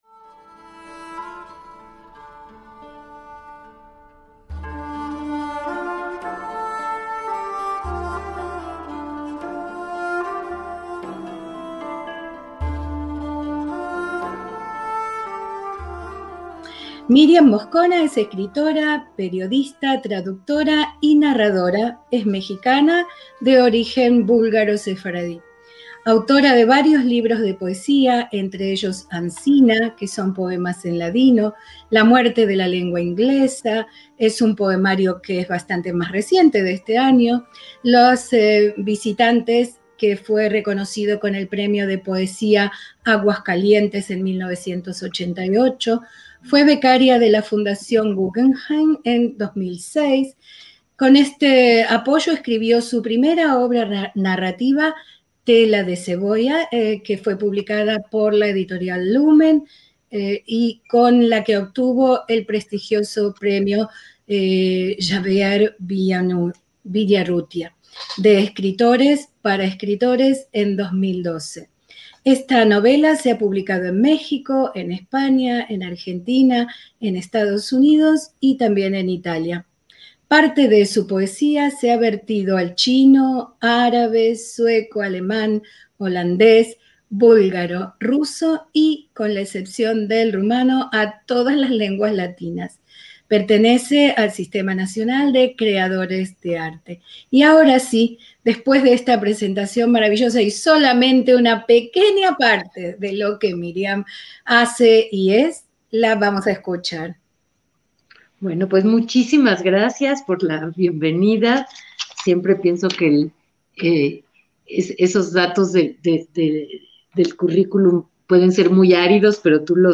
ACTOS "EN DIRECTO" - La escritora mexicana Myriam Moscona nos sorprende y emociona recuperando su infancia a través de sus historias personales, la relación entre la lengua, el ladino y cómo eso fue plasmado en sus libros.